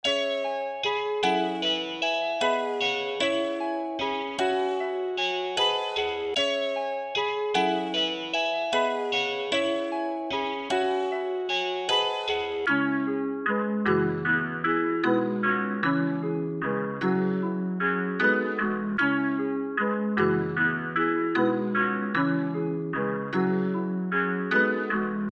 cash_out_76bpm_oz.mp3